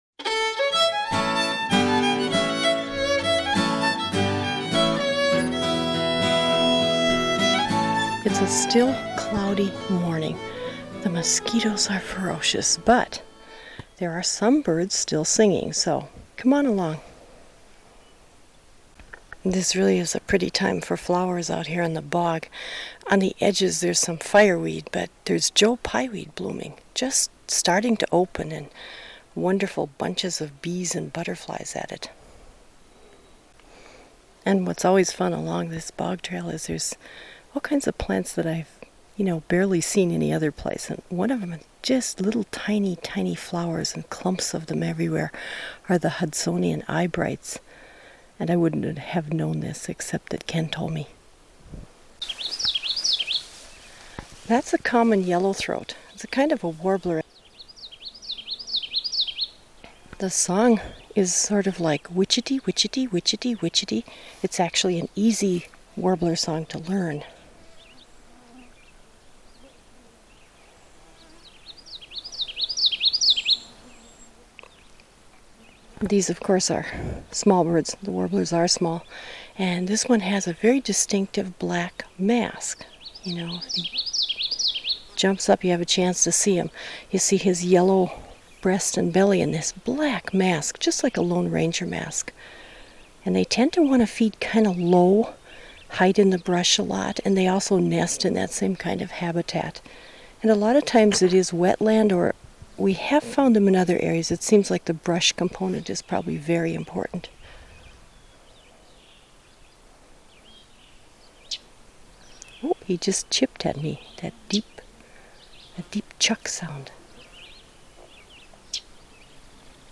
Common Yellow-Throat, Cedar Waxwing and Chestnut-Sided Warbler